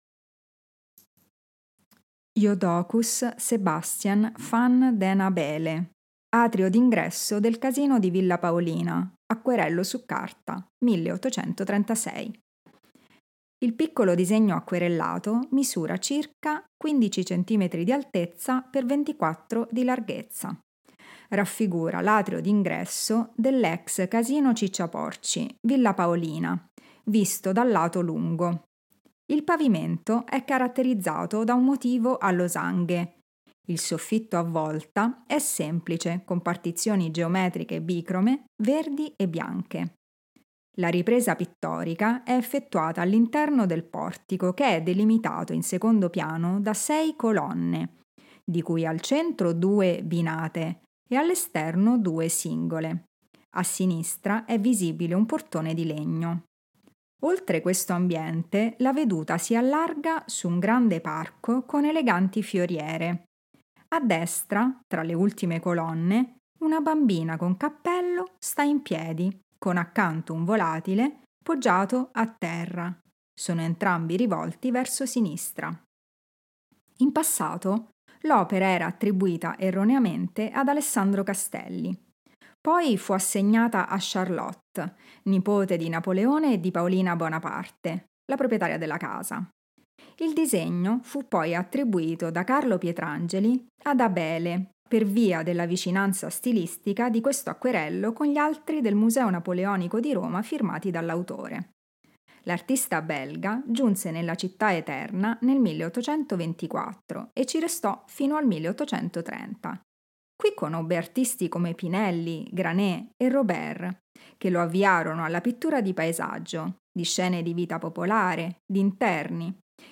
Audio-descrizioni sensoriali: